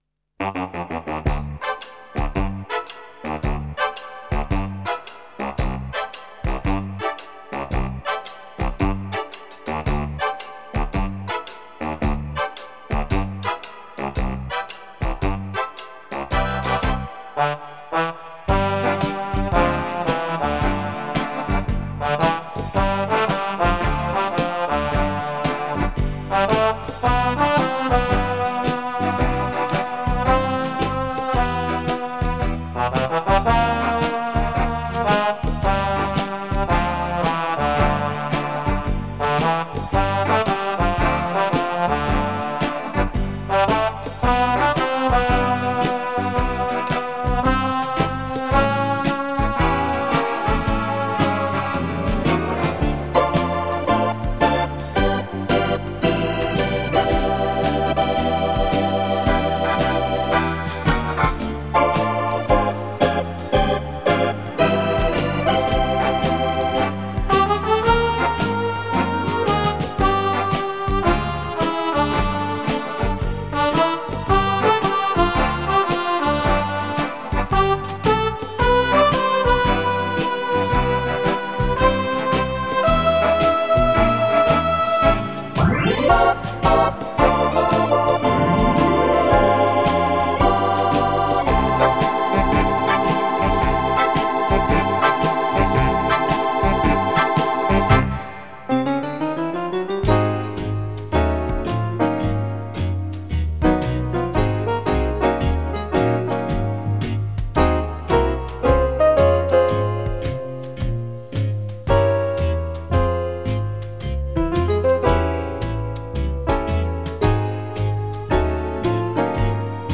Жанр: Instrumental, Tribut